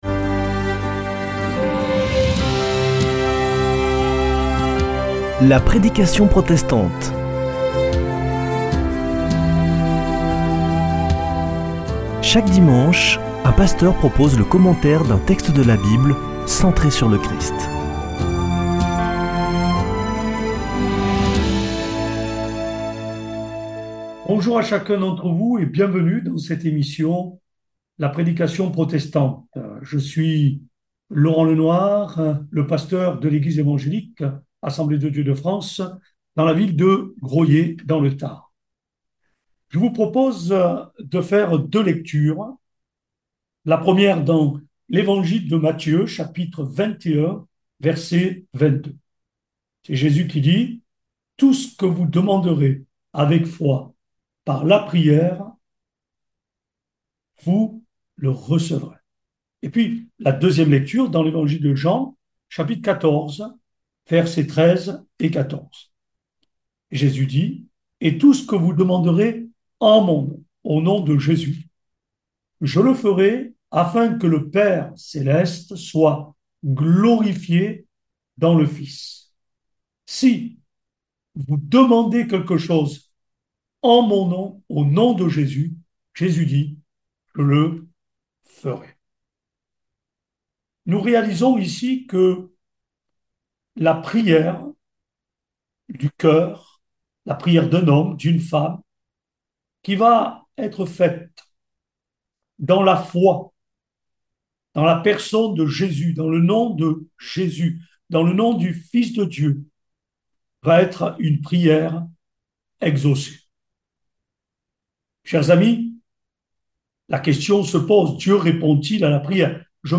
Accueil \ Emissions \ Foi \ Formation \ La prédication protestante \ Dieu répond-il à la prière ?